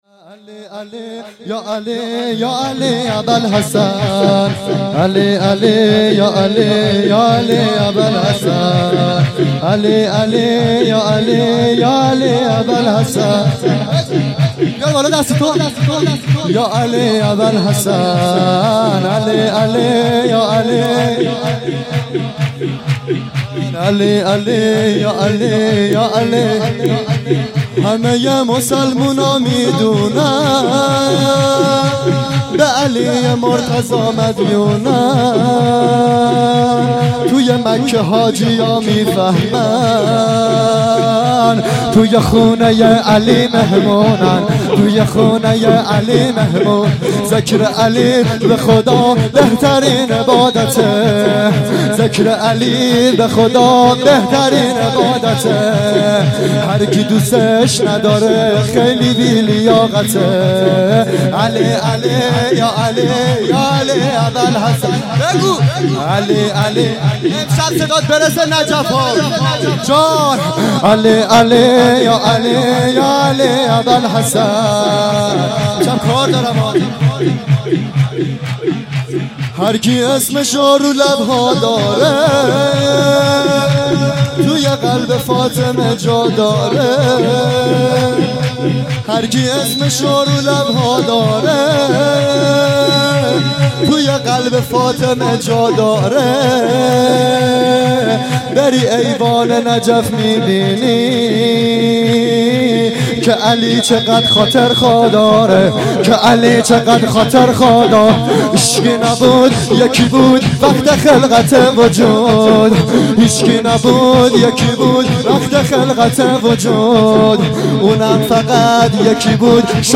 سرود | همه مسلمونا میدونن
میلاد پیامبر اکرم (ص)